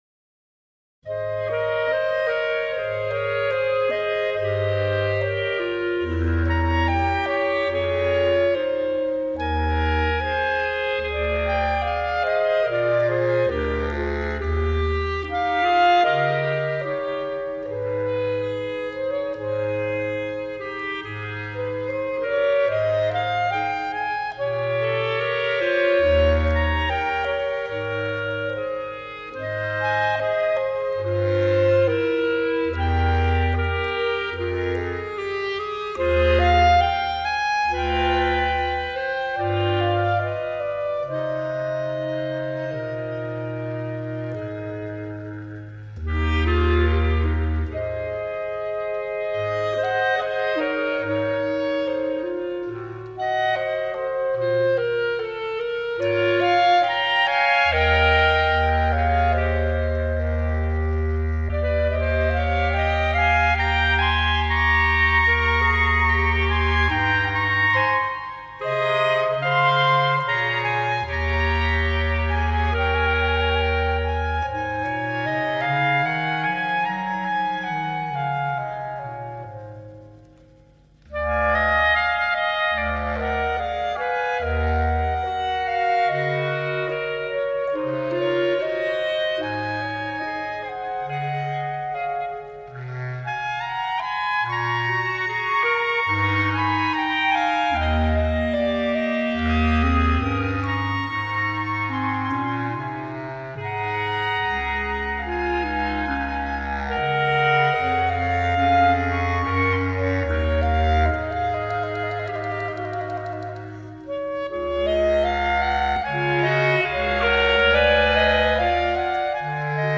Scored for 3 Bb Clarinets and 1 Bass Clarinet